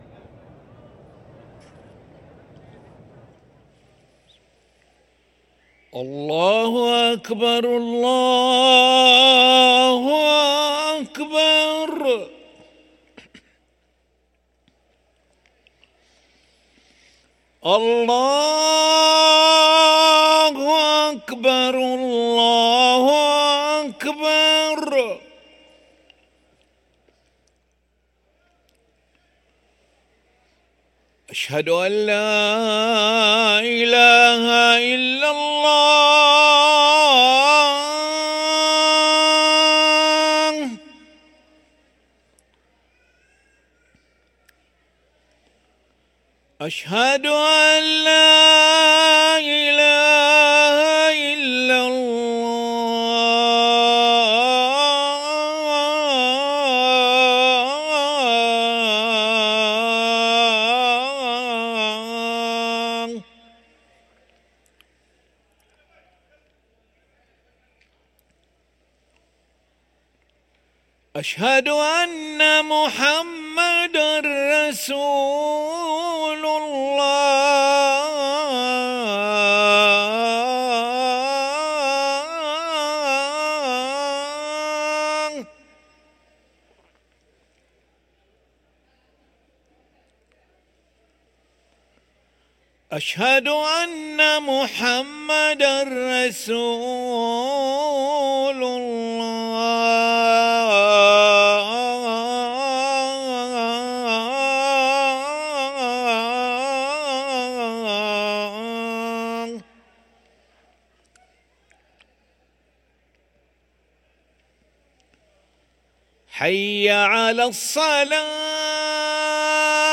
أذان العشاء للمؤذن علي ملا الخميس 1 صفر 1445هـ > ١٤٤٥ 🕋 > ركن الأذان 🕋 > المزيد - تلاوات الحرمين